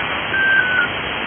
Бипер в конце передачи
Бипер в конце передачи, записано на КВ